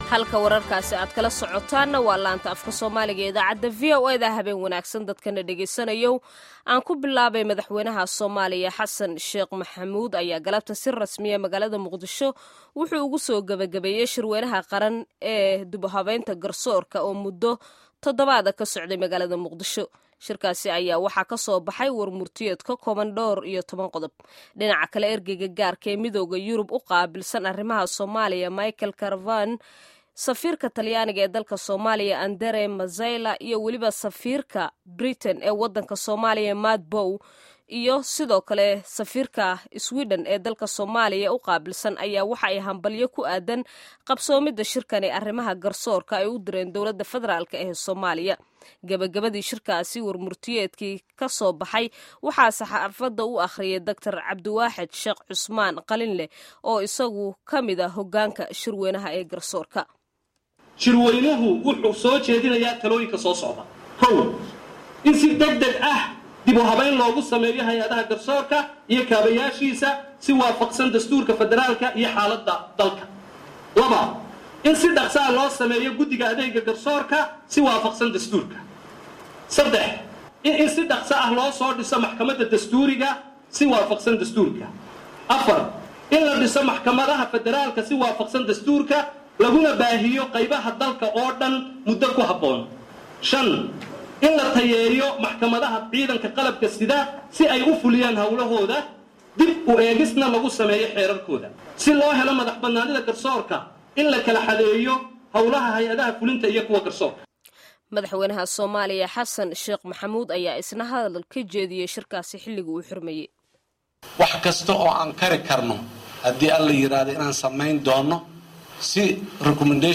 Warka Gabagabada Shirka Garsoorka